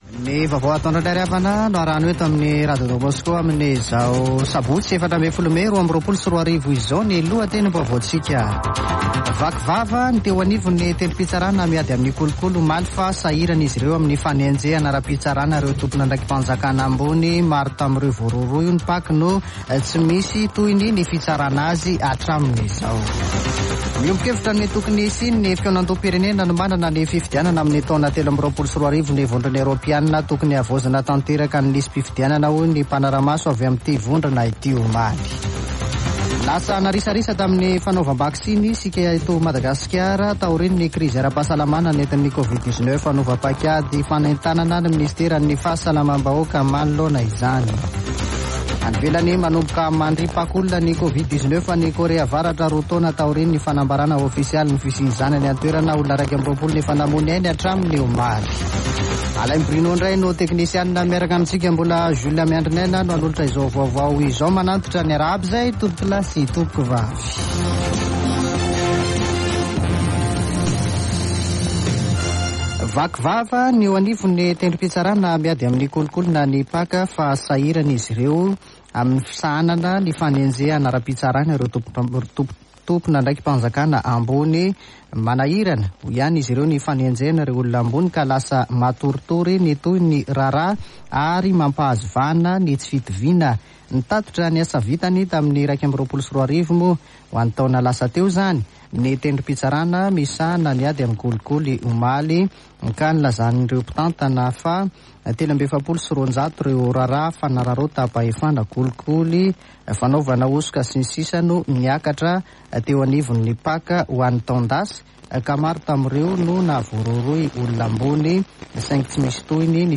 [Vaovao antoandro] Sabotsy 14 mey 2022